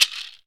MURDA_PERCUSSION_RAINSTICK.wav